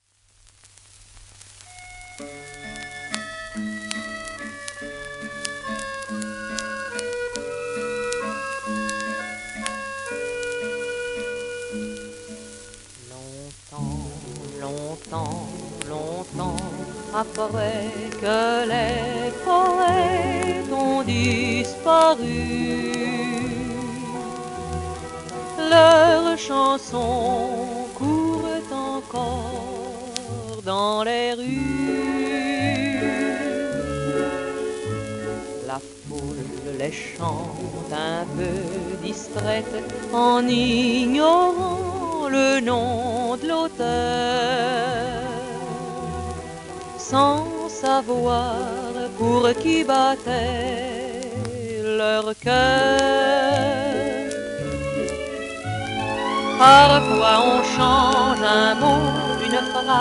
w/オーケストラ
戦後のフランスを代表するシャンソン歌手の一人で、100万枚以上のレコードセールスを上げた最初のフランス人女性歌手。